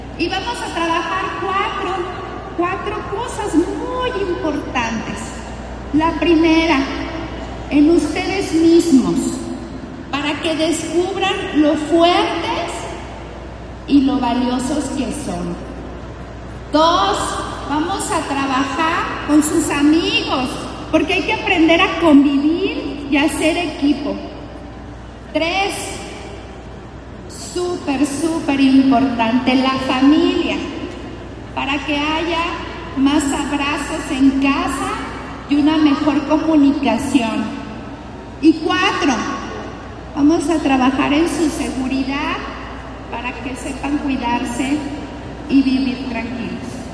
AudioBoletines
Valeria Alfaro, presidenta del Sistema DIF Municipal